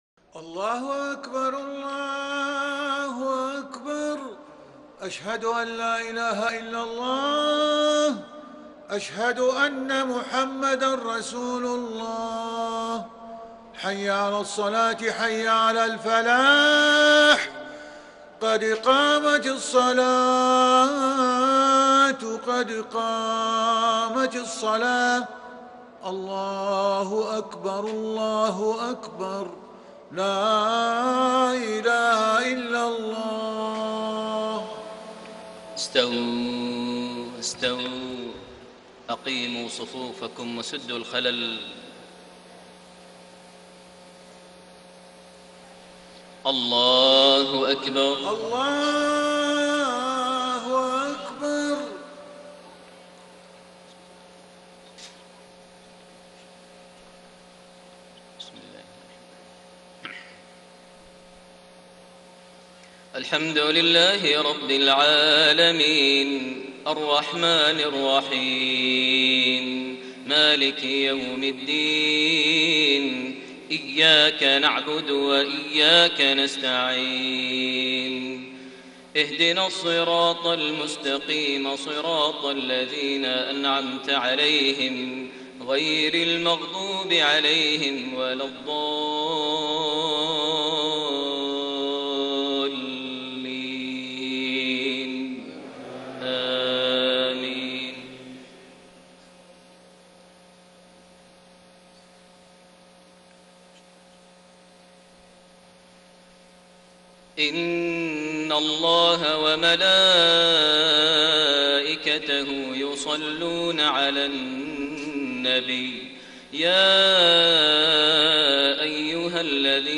صلاة العشاء 6 شعبان 1433هـ خواتيم سورة الأحزاب 56-73 > 1433 هـ > الفروض - تلاوات ماهر المعيقلي